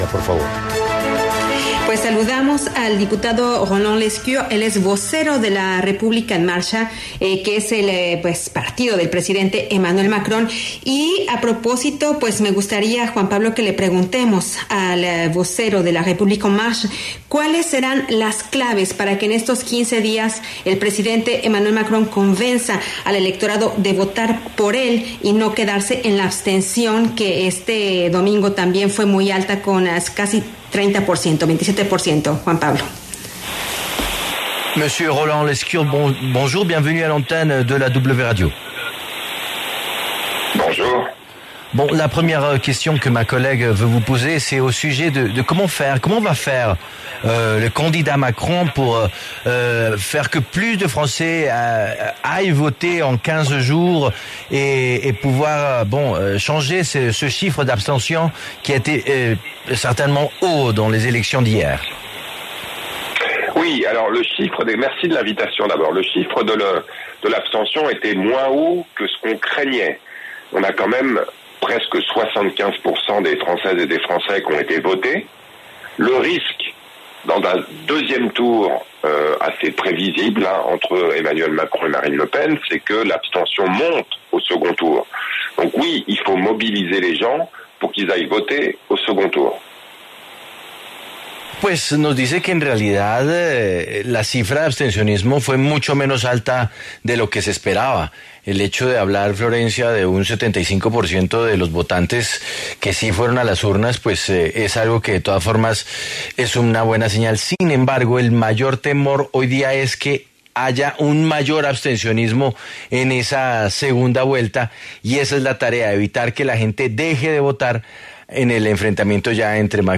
Roland Lescure, vocero de la República en Marcha, habló en La W tras el paso de Emmanuel Macron a la segunda vuelta de las presidenciales en Francia.